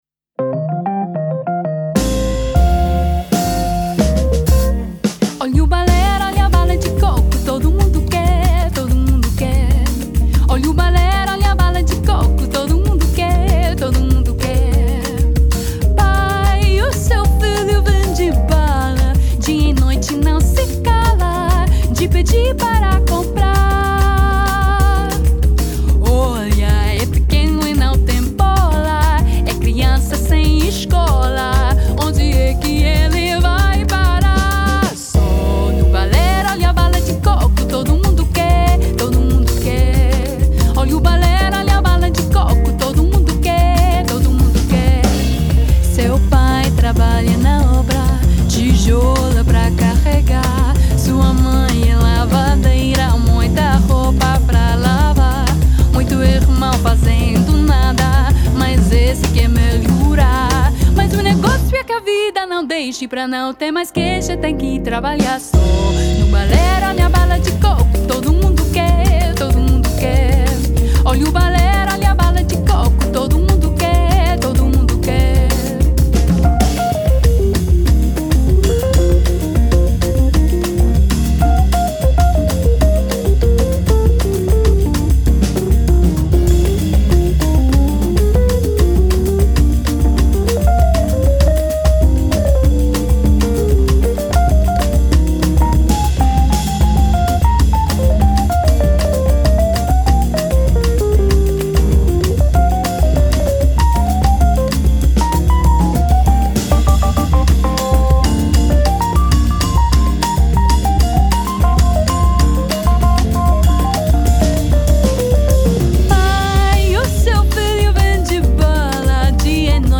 drumset, percussion, vocals
piano, Rhodes, vocals
guitar
bass
harmonica